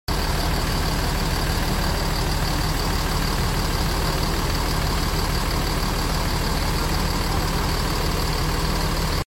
suara mesin bus mercy Oh1626 sound effects free download
suara mesin bus mercy Oh1626 renyah